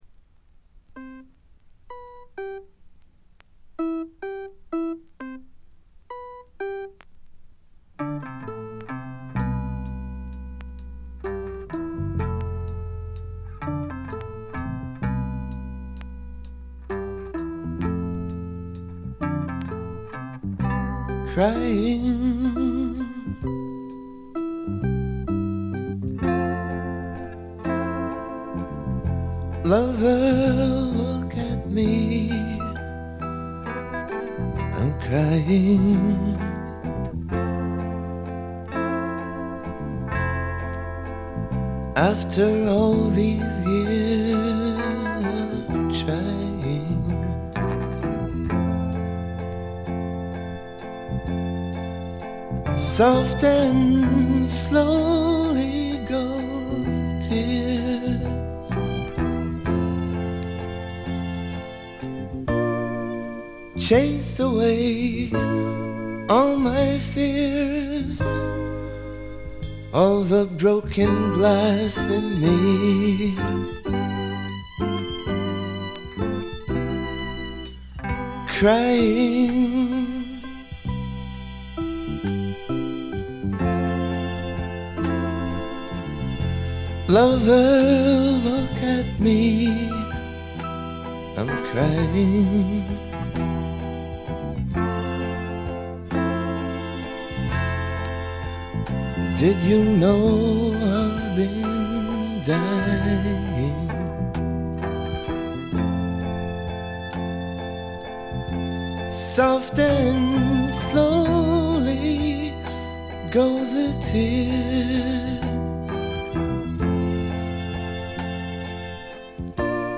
ほとんどノイズ感無し。
もともとは楽曲ライター志望だったようで曲の良さはもちろんのこと、ちょっとアシッドなヴォーカルが素晴らしいです。
メランコリックでビター＆スウィートな哀愁の名作。
試聴曲は現品からの取り込み音源です。